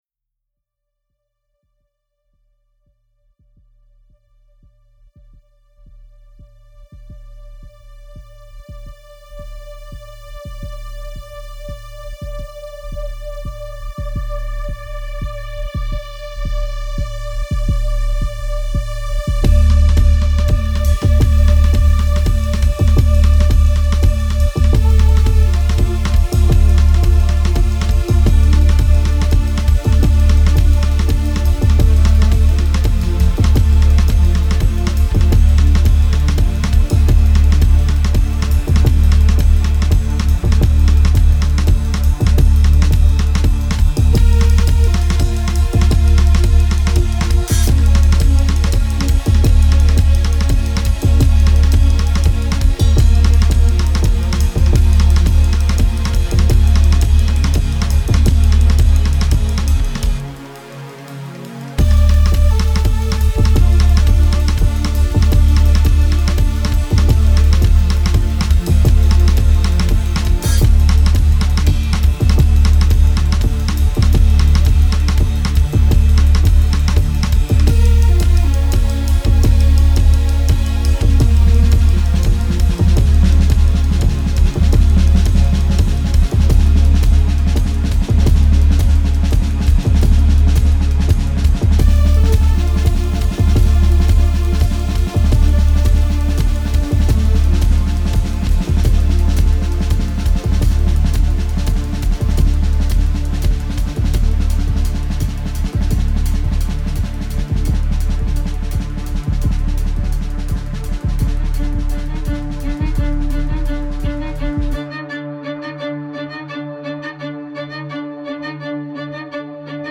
Genres: Experimental, Instrumental